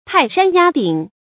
泰山壓頂 注音： ㄊㄞˋ ㄕㄢ ㄧㄚ ㄉㄧㄥˇ 讀音讀法： 意思解釋： 比喻遭遇到極大的壓力和打擊。